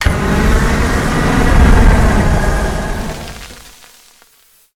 flamethrower_shot_05.wav